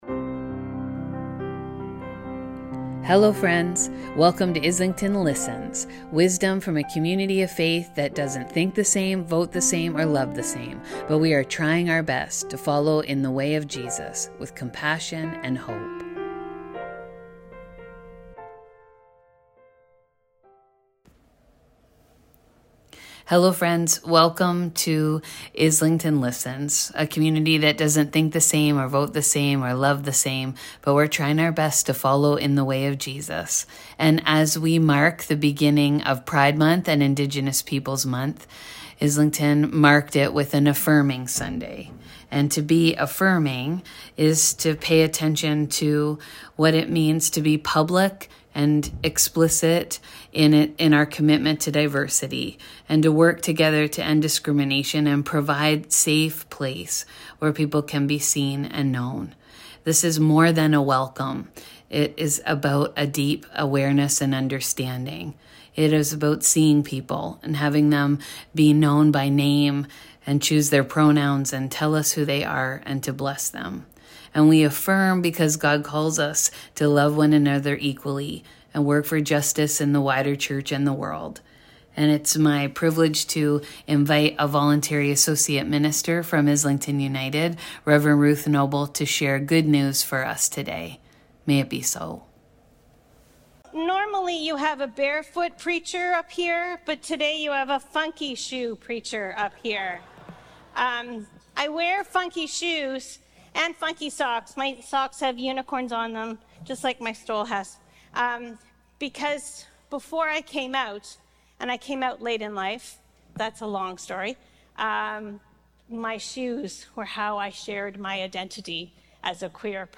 Affirm Sunday with Guest Preacher